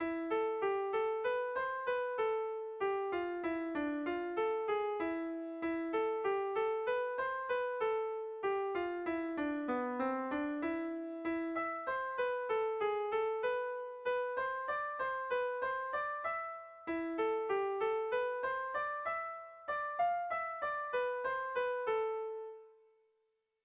Erlijiozkoa
A1A2BD